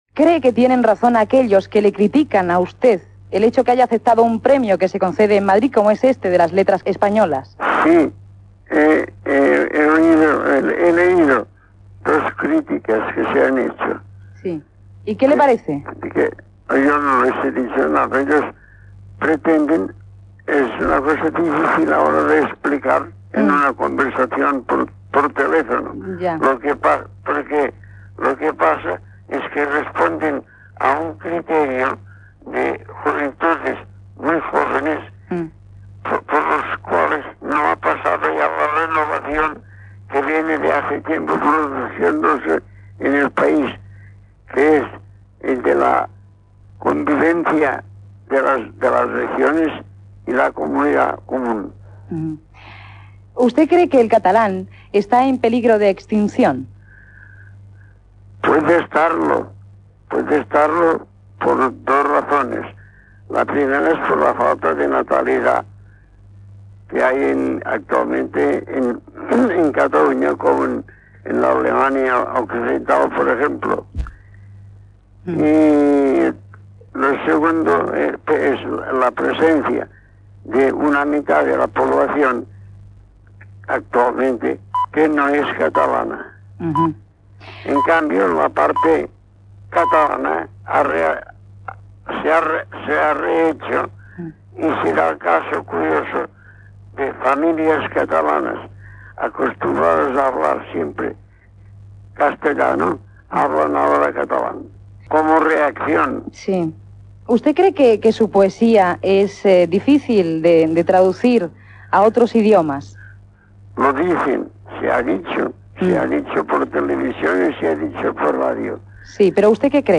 Entrevista al poeta Josep Vicenç Foix que ha rebut el Premio Nacional de las Letras Españolas. S'hi parla del premi, de la llengua catalana, de la seva poesia, de la pastisseia familiar del barri de Sarrià